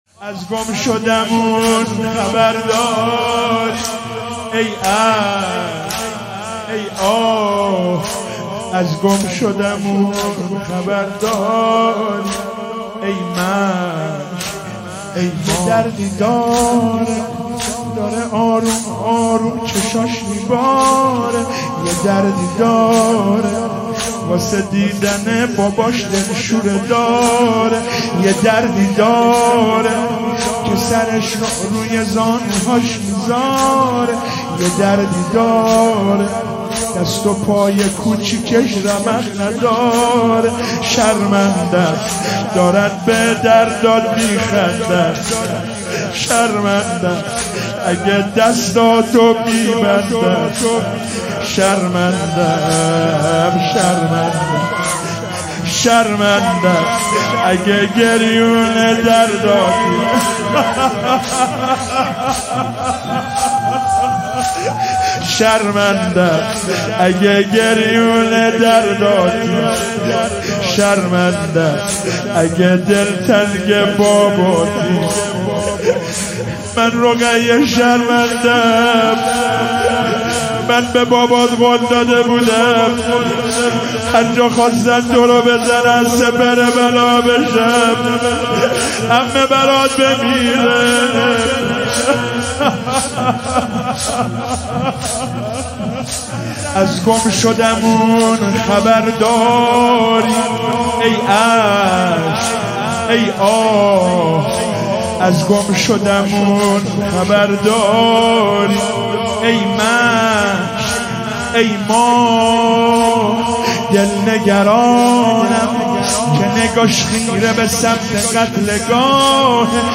محرم الحرام 1441